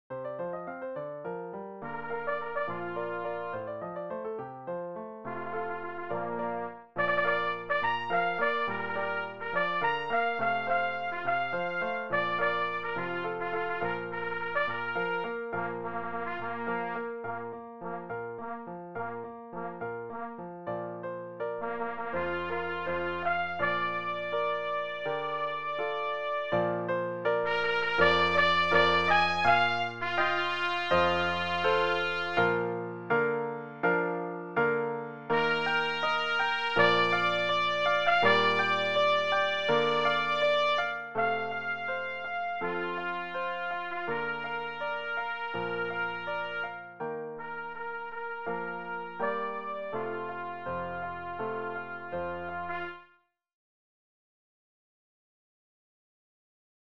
pour les Cuivres accompagnés
pour Clairon Si b.